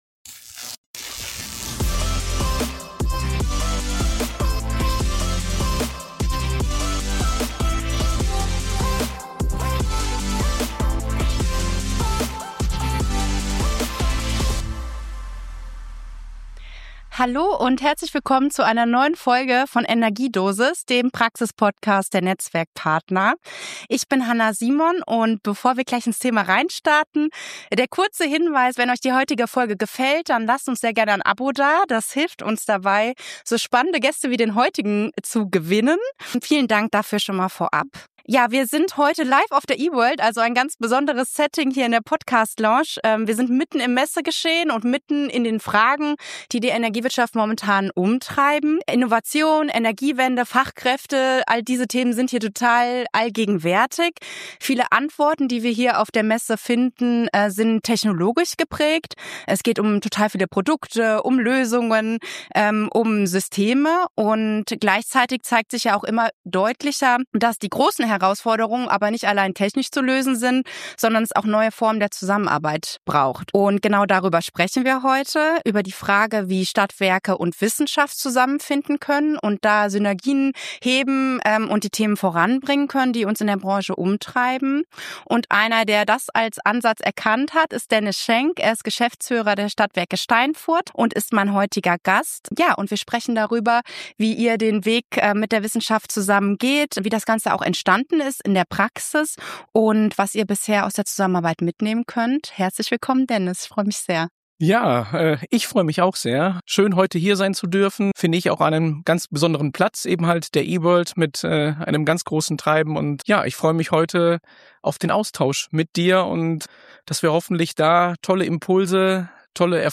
Direkt vom Messestand nehmen wir die Stimmung der Branche auf: volle Hallen, intensive Gespräche, spürbarer Innovationsdruck. Themen wie Fachkräftemangel, Digitalisierung, Kooperationen und praxisnahe Forschung ziehen sich durch viele Diskussionen.